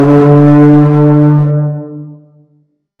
Ship Horn Blast
A deep, powerful ship horn sounding across a foggy harbor with long reverb tail
ship-horn-blast.mp3